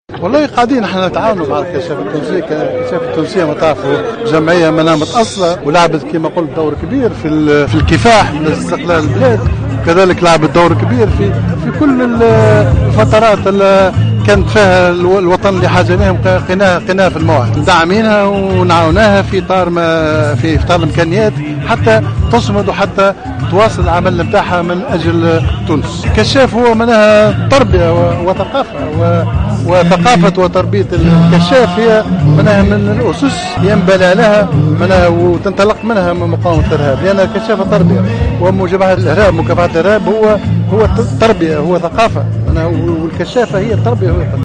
Le chef du gouvernement Habib Essid a présidé ce lundi 10 août 2015, l’inauguration de le troisième Jamboree des scouts tunisiens.